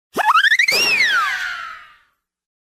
Смешные звуки ударов для монтажа
Звук, когда кто-то вертится, вертится о потом либо падает, либо ударяет